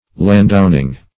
Search Result for " landowning" : The Collaborative International Dictionary of English v.0.48: Landowning \Land"own`ing\, n. The owning of land.
landowning.mp3